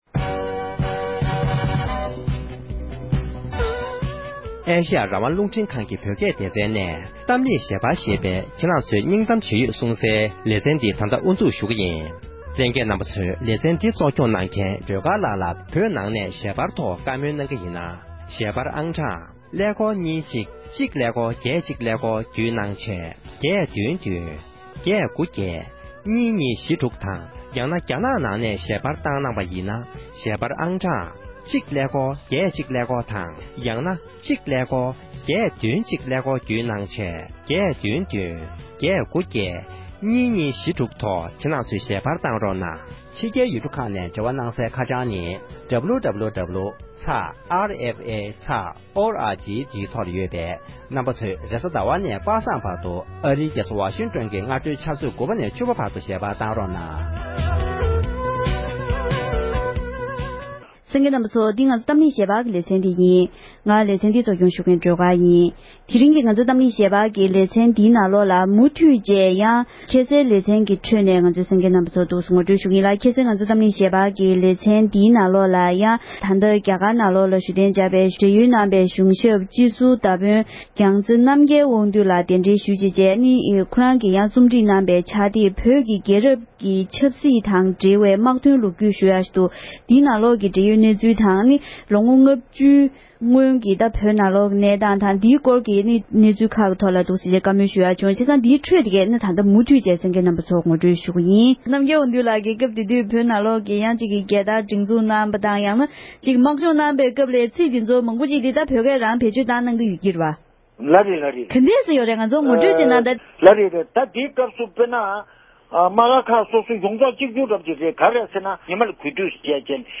བཀའ་འདྲི་ཞུས་པ’འི་ལེ་ཚན་གཉིས་པ་འདི་གསན་རོགས་གནོངས༎